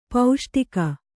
♪ pauṣṭika